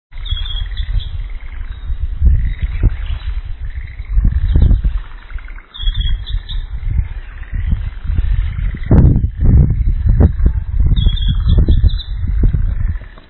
鳴 き 声：地鳴きはチッ、チッと鳴く。
速くて声量がある。
雄は高い枝の上で囀る。
鳴き声１